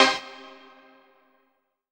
HOUSE104.wav